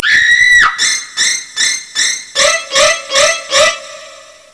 scream-and-music_24883.mp3